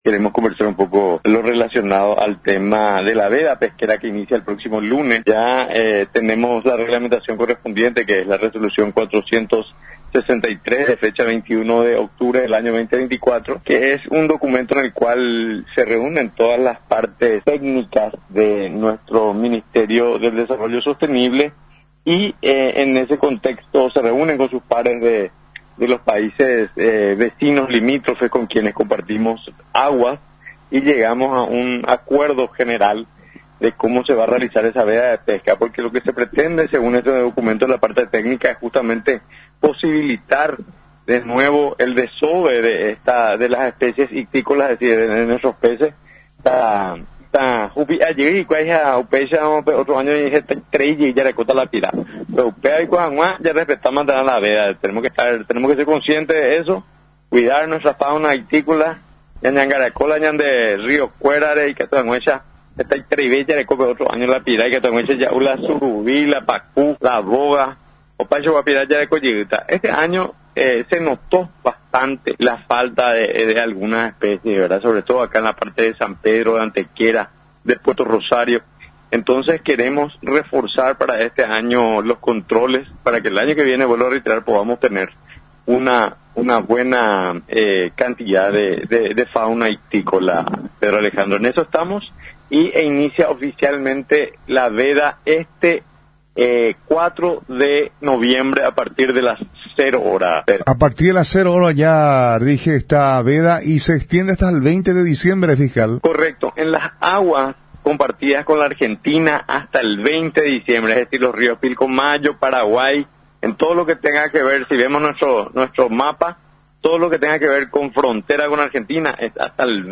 Nota: Abg. Daniel Benítez, agente fiscal especializado en Delitos Ambientales